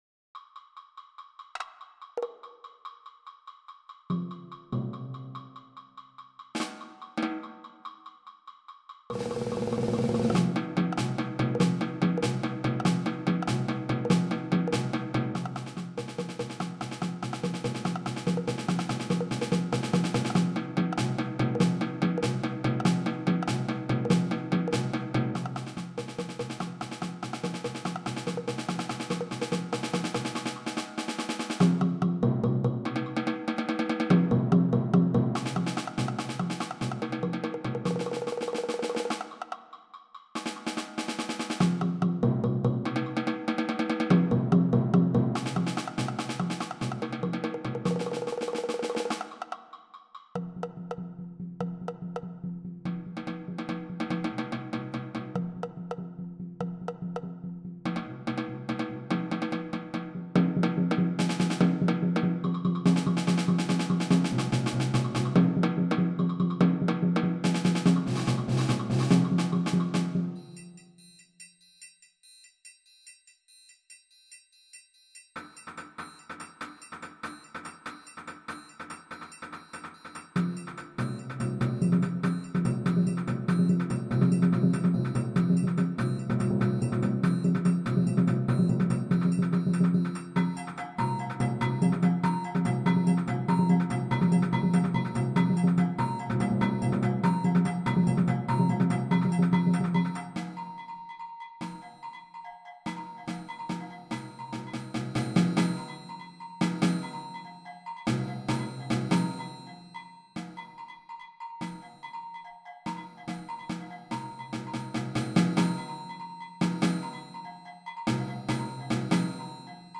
stimmlose Percussion.